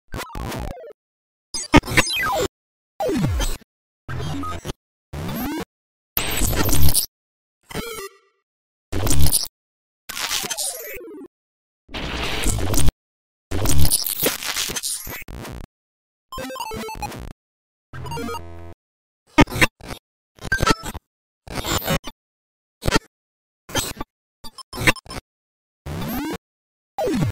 Meme Sound Effects
8-Bit-Pixel-meme-Sound-Effects.mp3